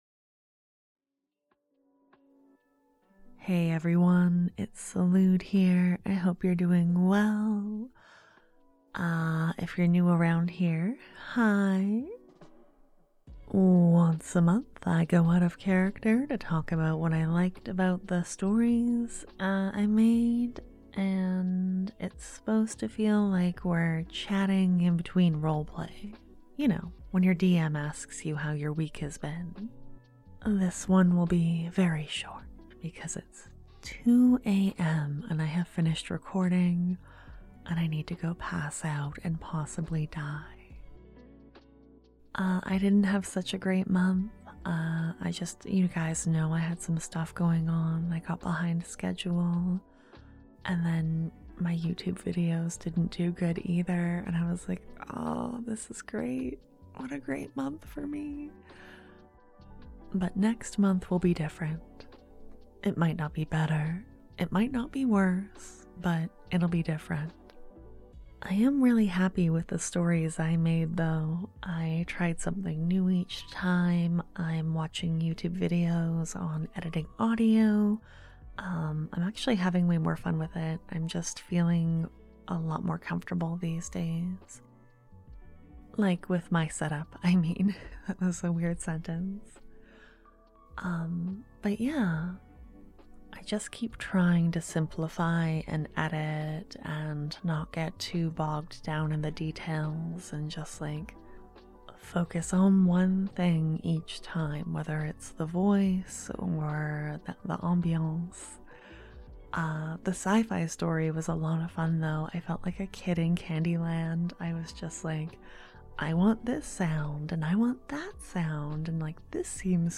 Sometimes I go 'out of character' and speak as myself for five minutes or so. I ramble about what I liked about my stories and what I'm focusing on going forward. It also gives me a chance to thank you for your support with my voice rather than typing words at you~(It's not listed as a perk on my Patreon page because it's not really a perk~ >>)The audio isn't edited much and it's not my usual production quality, just as a heads up~Enjoy~!XOXO